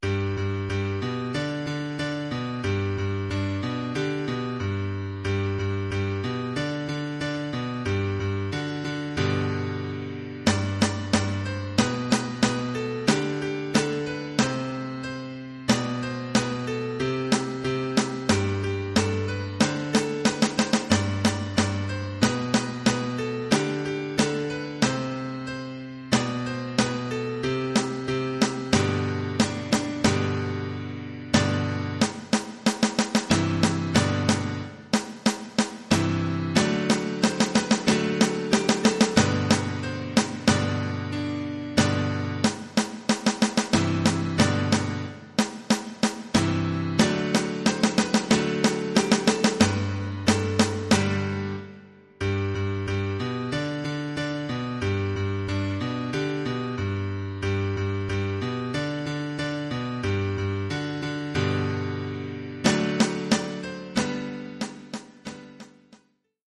Oeuvre pour percussions et piano.